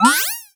cartoon_boing_jump_12.wav